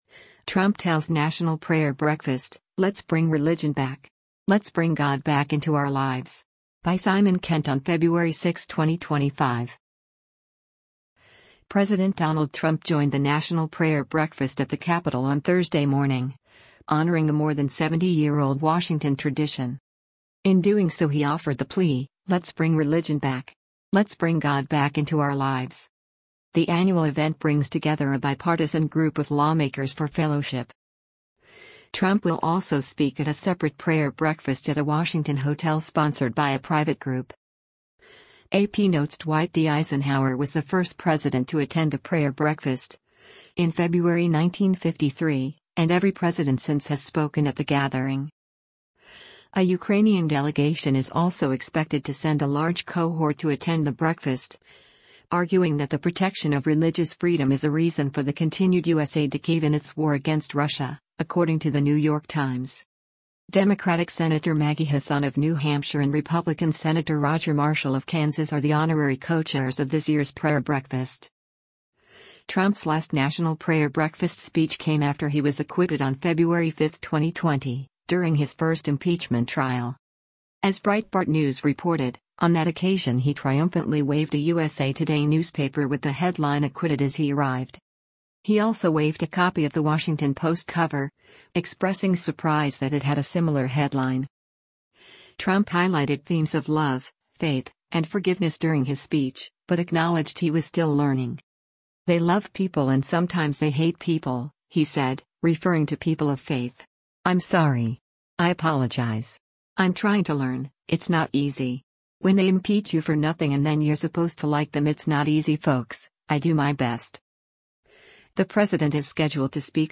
President Donald Trump joined the National Prayer Breakfast at the Capitol on Thursday morning, honoring a more than 70-year-old Washington tradition.